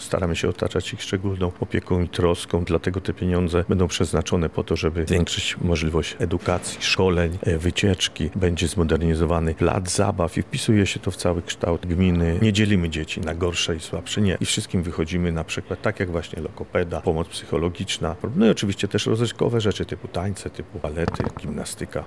Paweł Dąbrowski-mówi Paweł Dąbrowski, burmistrz gminy Urzędów